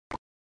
Clash Royale Click Sound Button - Free Download & Play